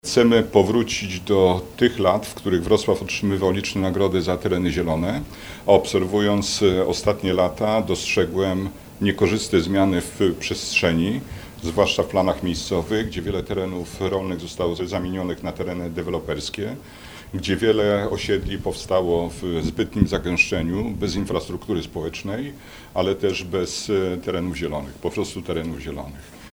Platforma Obywatelska zorganizowała konferencję w sprawie poparcia Zielonego Klina Południa Wrocławia.
Mówi Senator RP Bogdan Zdrojewski.